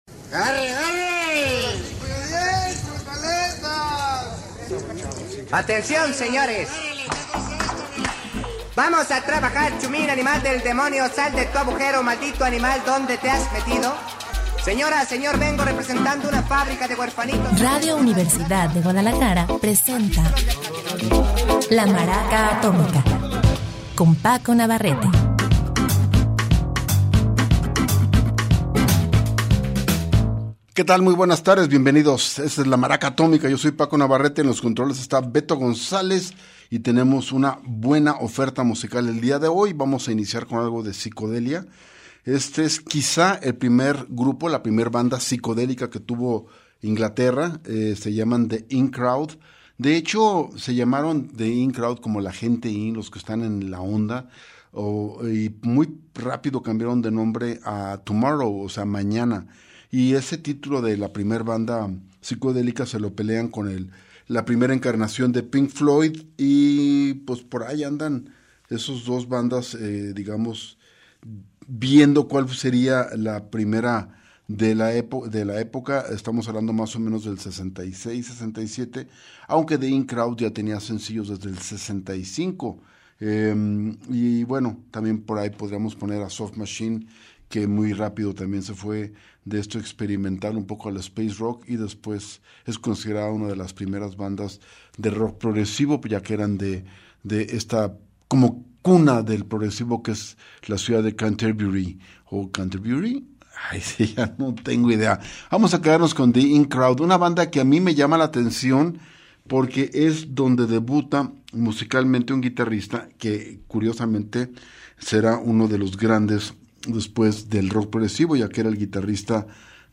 tenemos una excelente oferta musical el dia de hoy, empezamos por la piscodelia